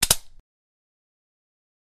weaponDrop.wav